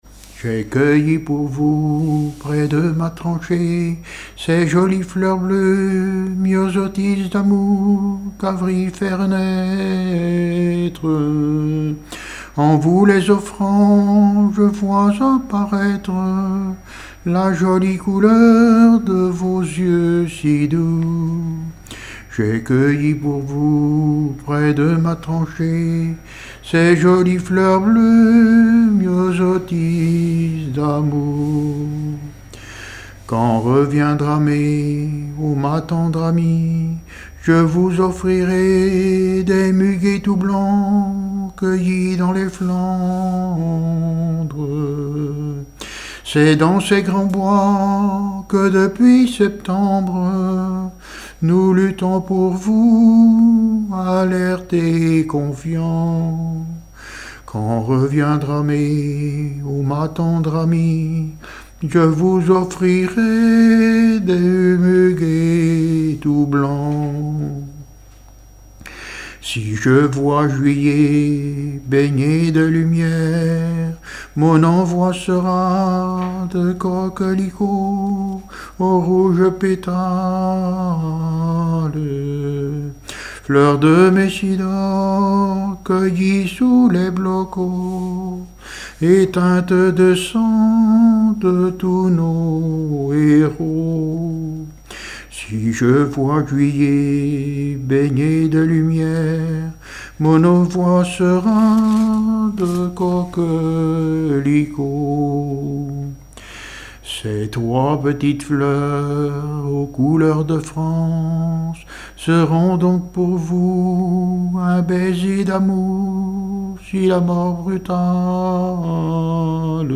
Mémoires et Patrimoines vivants - RaddO est une base de données d'archives iconographiques et sonores.
Témoignages et chansons
Pièce musicale inédite